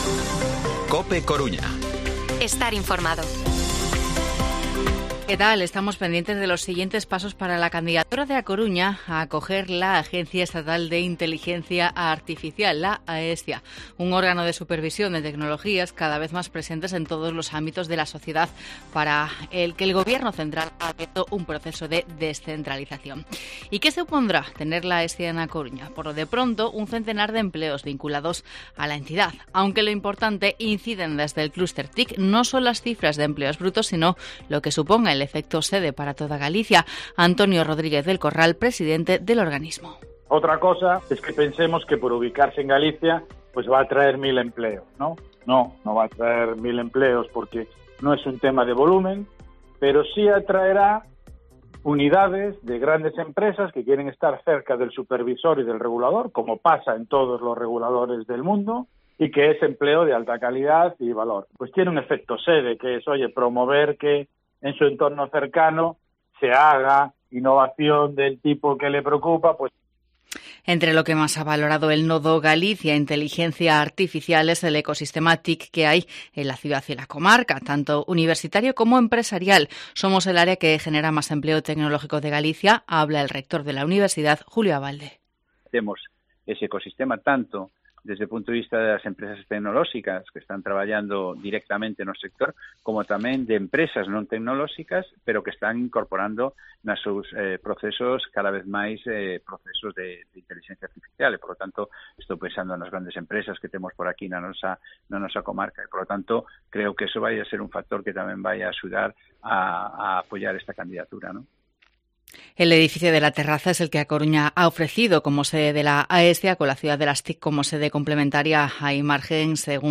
Informativo Mediodía COPE Coruña martes, 25 de octubre de 2022 14:20-14:30